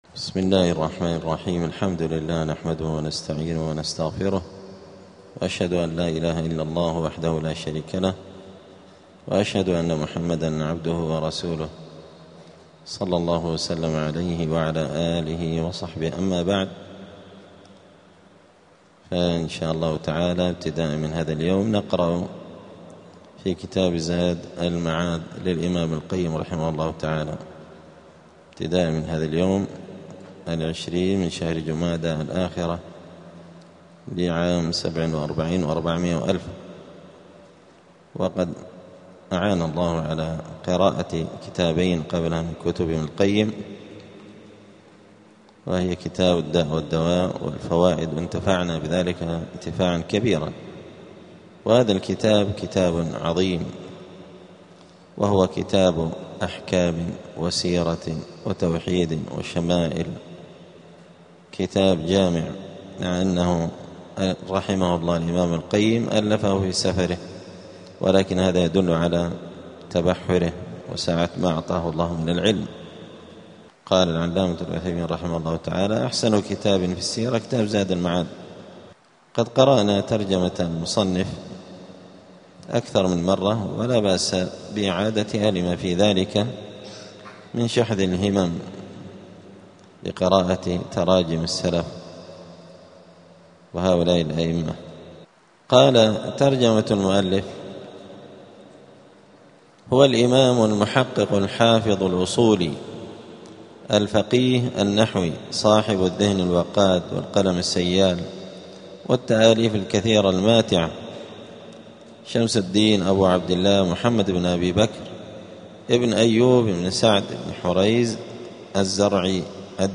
*الدرس الأول (1) {مقدمة المؤلف}.*